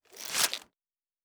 Medieval Combat Sounds
Stab 24_3.wav